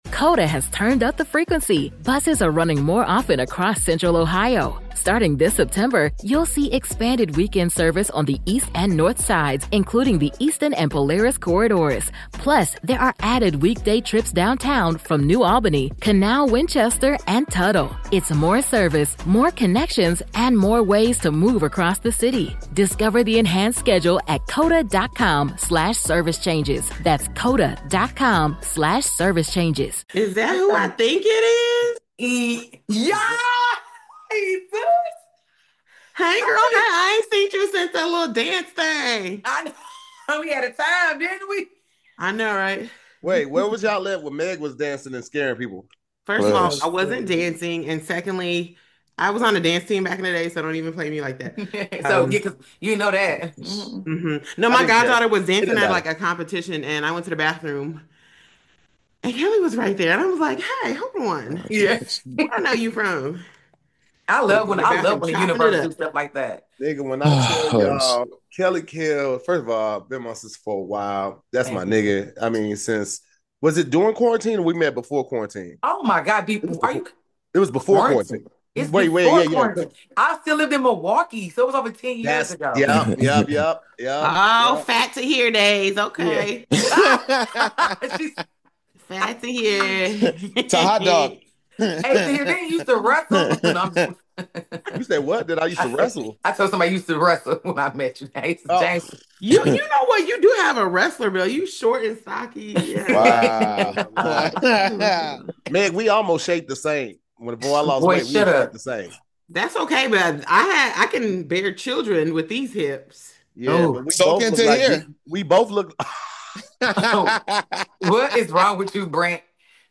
Each week, the SquADD will debate topics and vote at the end to see what wins.